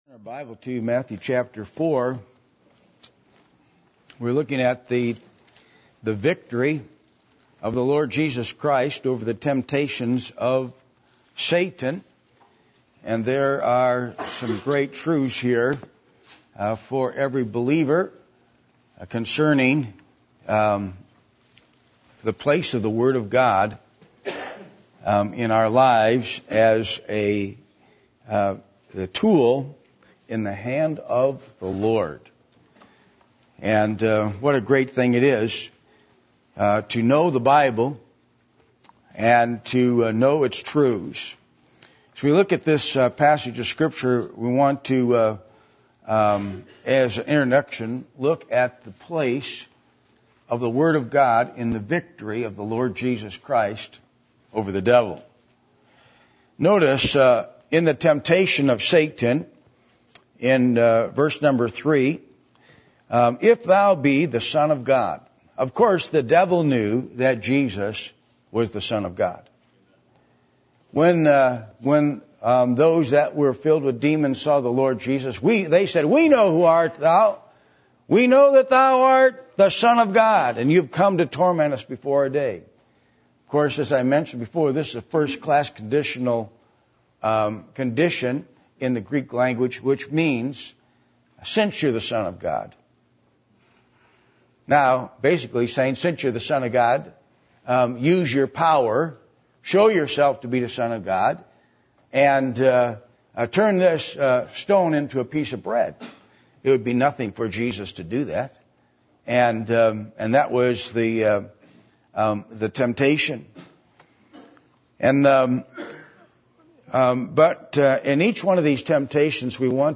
Matthew 4:1-11 Service Type: Sunday Morning %todo_render% « Abiding In The Vine What Does God Ask From Us When We Come To Church?